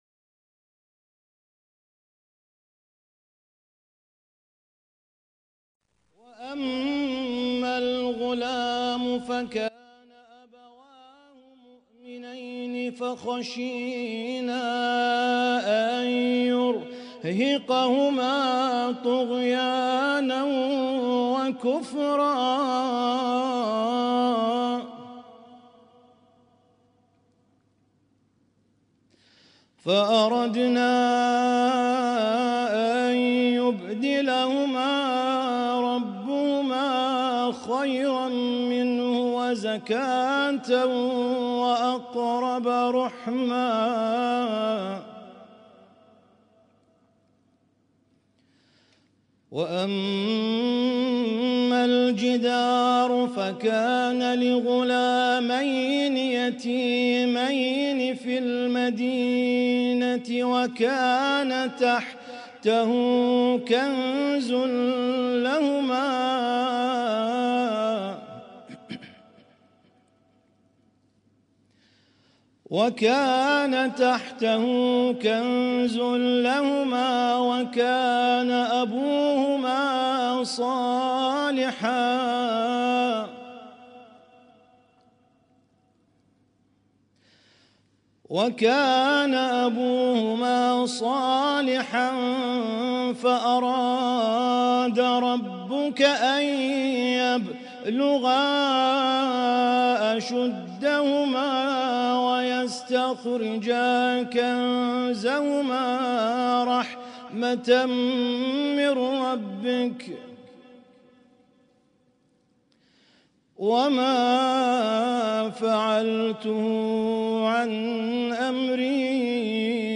Husainyt Alnoor Rumaithiya Kuwait
اسم التصنيف: المـكتبة الصــوتيه >> القرآن الكريم >> القرآن الكريم - شهر رمضان 1446